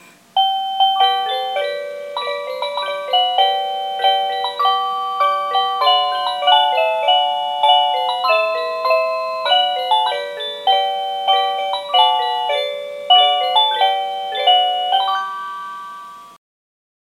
03-Cuckoo-Tune.mp3